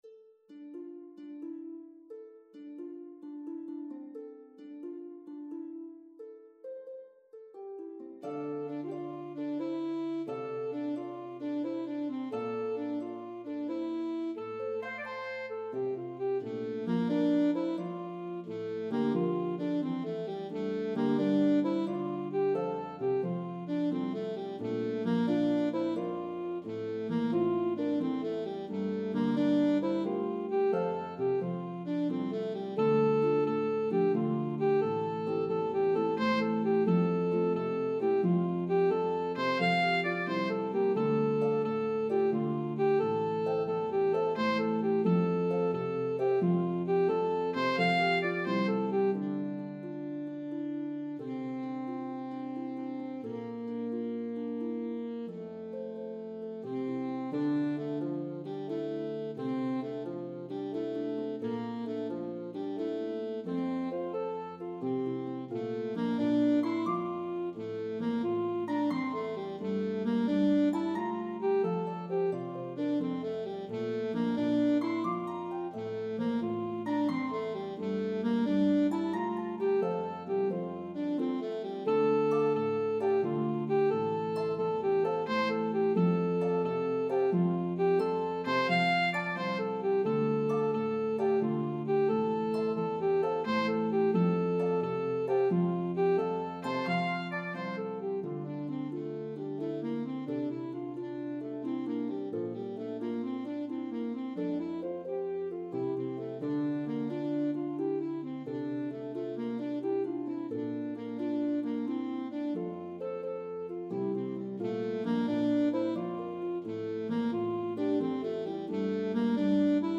The melodies are shared between instruments.
This entertaining 5+ minute medley begins sweetly
slip jig in 9/8
reel in 4/4). Lastly, travels up a fifth
jig in 6/8